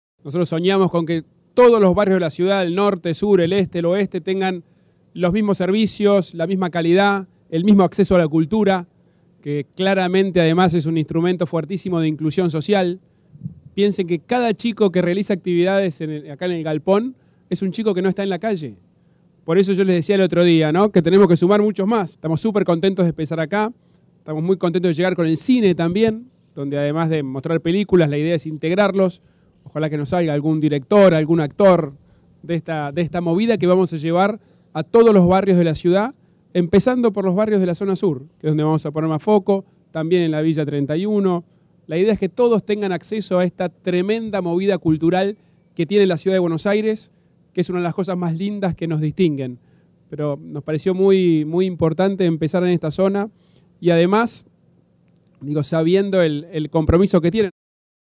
De la presentación, que se realizó en el barrio Piedrabuena, participaron también el vicejefe de Gobierno, Diego Santilli, y los ministros de Cultura, Darío Lopérfido, y de Desarrollo Humano y Hábitat, Guadalupe Tagliaferri.
Horacio rodriguez larreta presento el prgrama arte en barrios